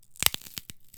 vegcrunch.wav